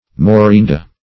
Search Result for " morinda" : The Collaborative International Dictionary of English v.0.48: Morinda \Mo*rin"da\, n. (Bot.) A genus of rubiaceous trees and shrubs, mostly East Indian, many species of which yield valuable red and yellow dyes.